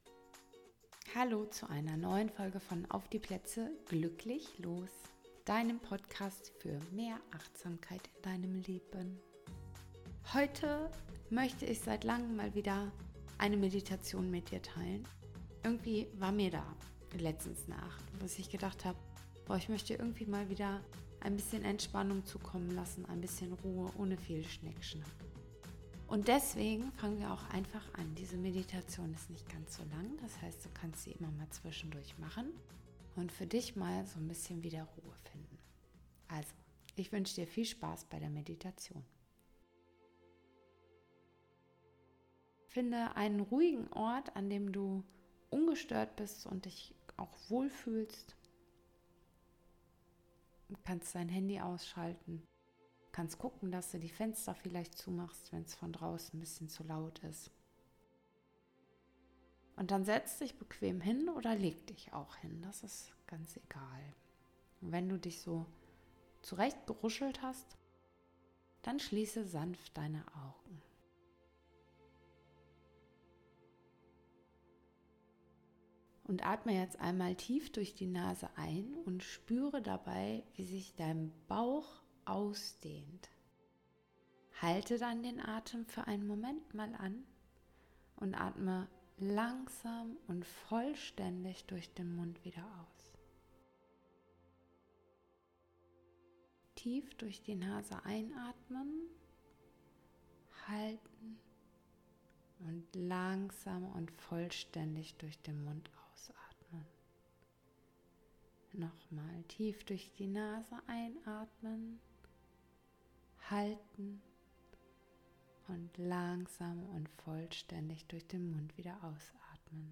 Meditation | Dein Ruheort